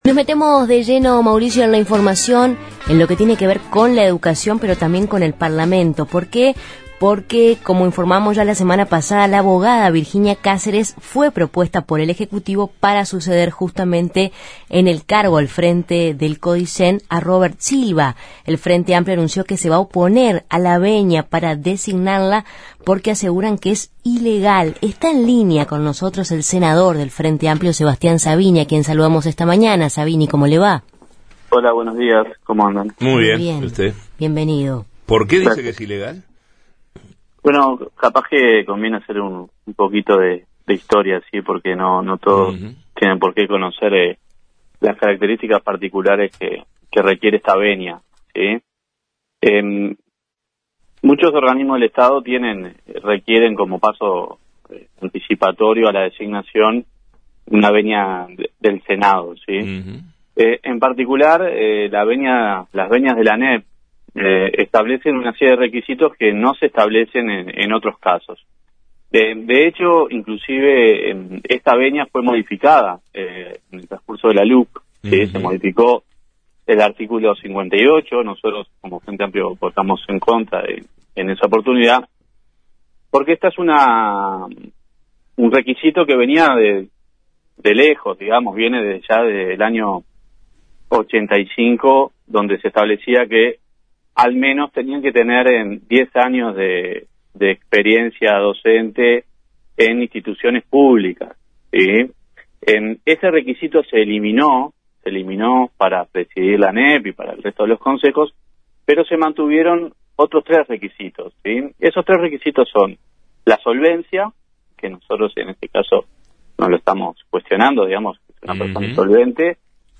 En Justos y pecadores entrevistamos al senador frenteamplista Sebastián Sabini, en torno a la propuesta del Poder Ejecutivo que Virginia Cáceres sea la sucesora de Robert Silva en el Codicen, a la que la coalición de izquierda considera ilegal.